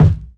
Medicated Kick 2.wav